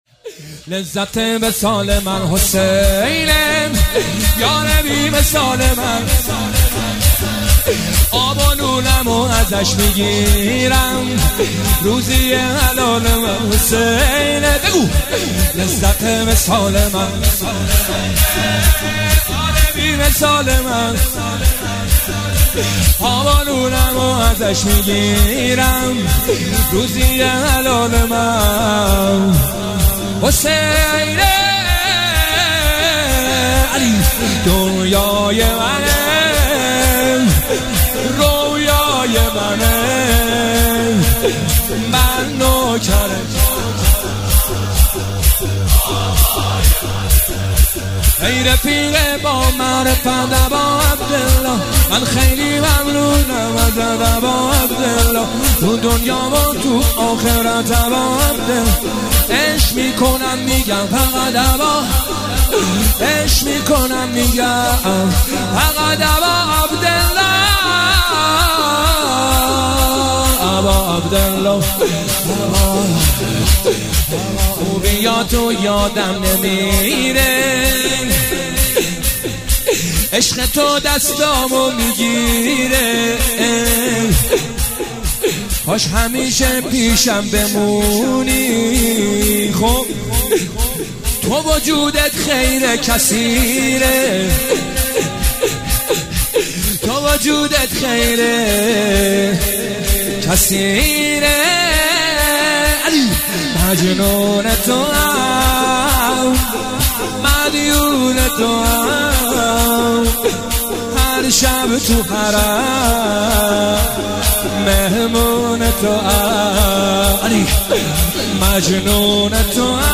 جلسه هفتگی 20 اردیبهشت 1404